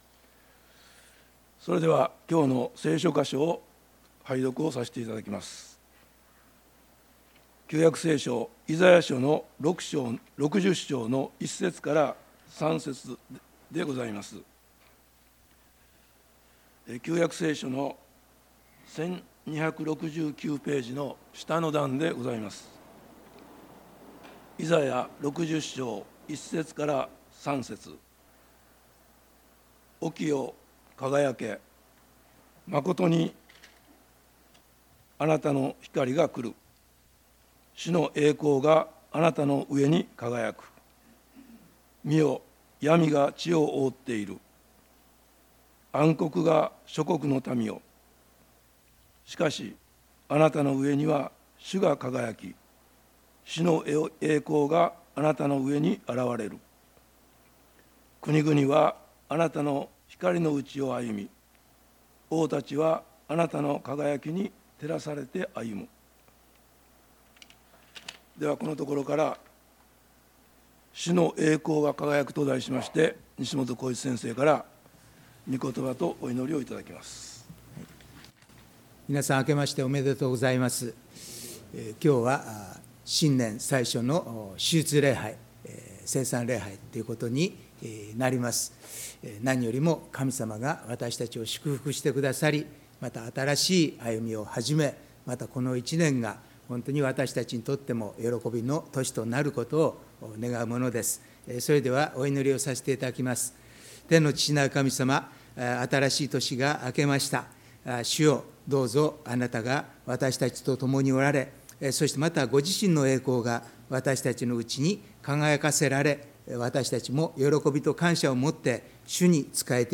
礼拝メッセージ「主の栄光が輝く」│日本イエス・キリスト教団 柏 原 教 会